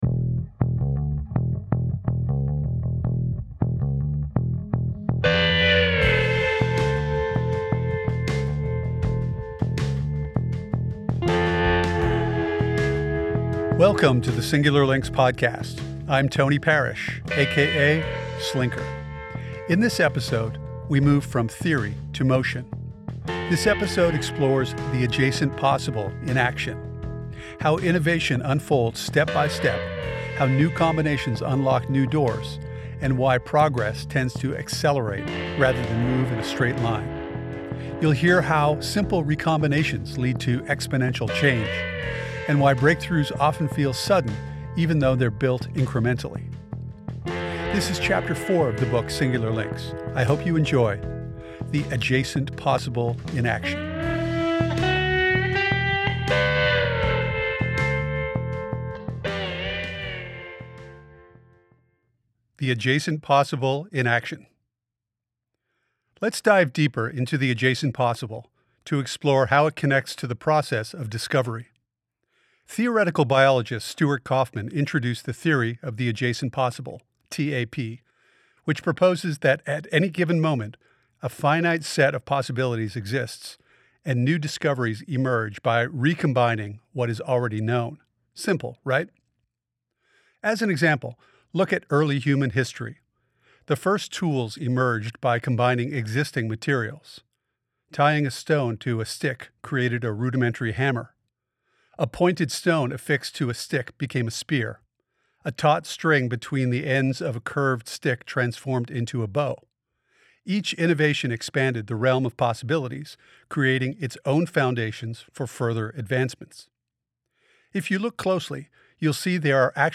This episode features Chapter Four of the audio book Singular Links.